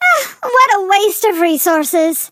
flea_kill_vo_02.ogg